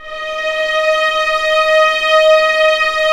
Index of /90_sSampleCDs/Roland LCDP13 String Sections/STR_Violins IV/STR_Vls7 _ Orch